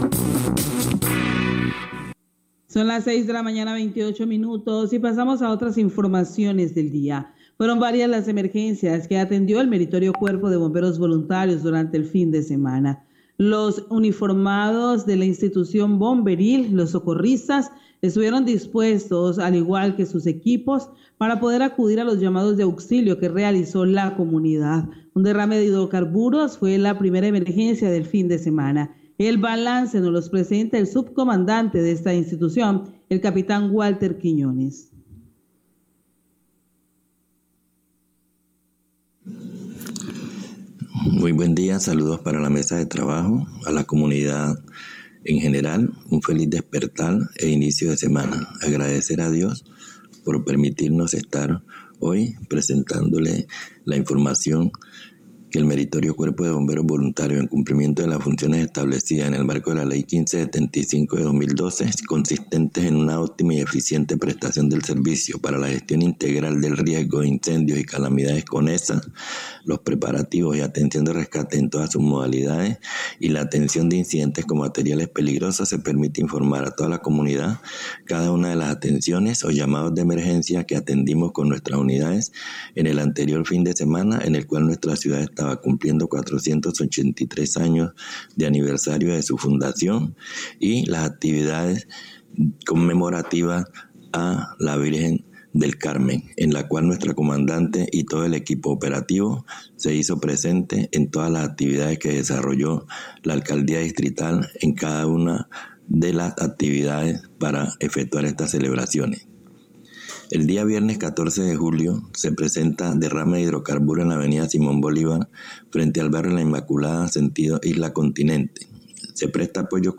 Radio
reporte de emergencias